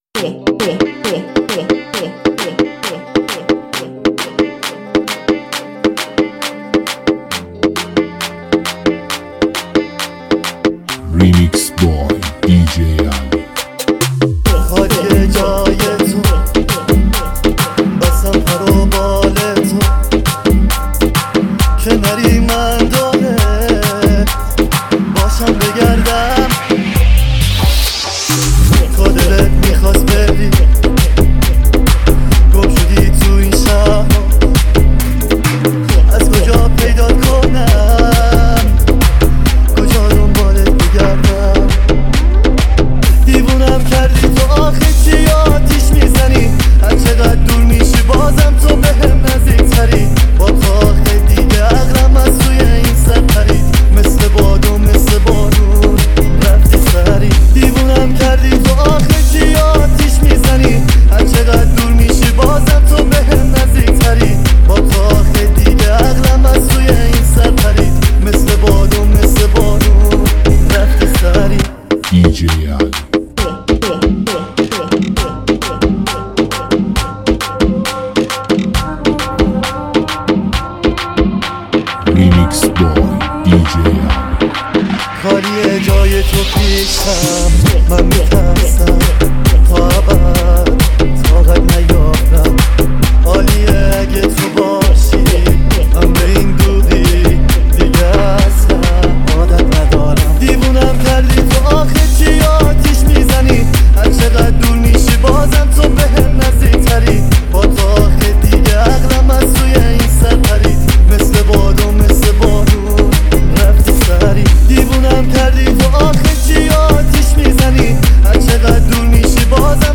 آهنگ شاد و بیس‌دار
بیت‌های مدرن و شاد